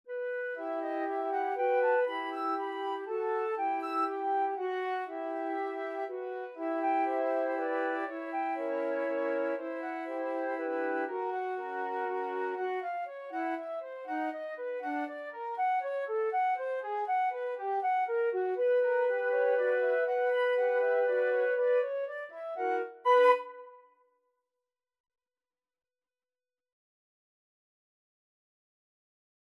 Free Sheet music for Flute Quartet
Flute 1Flute 2Flute 3Flute 4
E minor (Sounding Pitch) (View more E minor Music for Flute Quartet )
3/4 (View more 3/4 Music)
Classical (View more Classical Flute Quartet Music)
grieg_peer_gynt_4FL.mp3